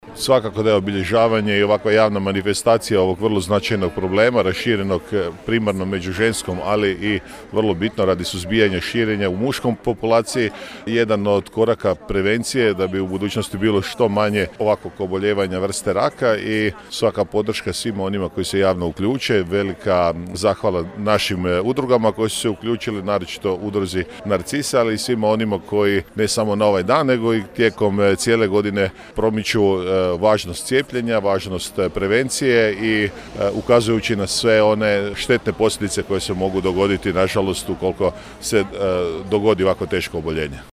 U organizaciji Lige protiv raka Koprivničko-križevačke županije i đurđevačke Udruge žena s bolestima dojke Narcise, na Trgu sv. Jurja u Đurđevcu obilježen je Nacionalni dan borbe protiv raka vrata maternice – Dan mimoza.
Akciju je okupila brojne građane, a podršku su dali i gradonačelnik grada Đurđevca Hrvoje Janči i predsjednik Gradskog vijeća i saborski zastupnik Željko Lacković: